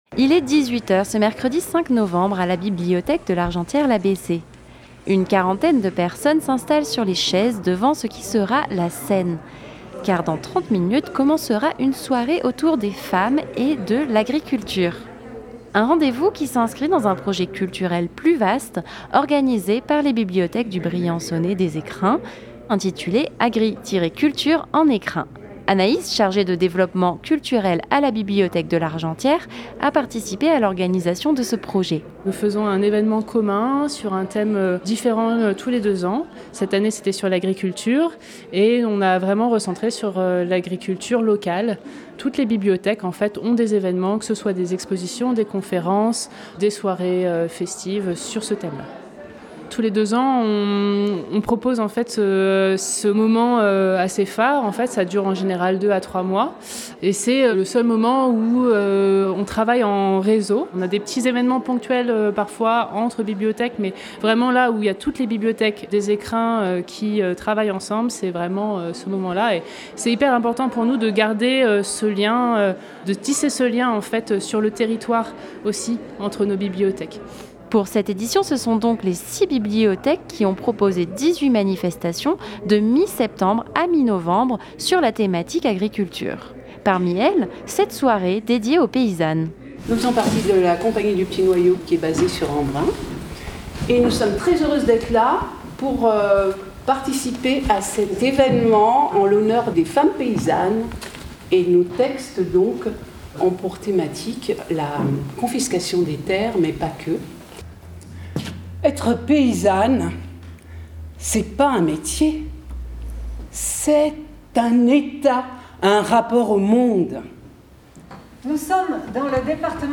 Dans ce cadre, une soirée s'est déroulée à la bibliothèque de l’Argentière-la Bessée avec pour thème les femmes et l’agriculture.
Reportage.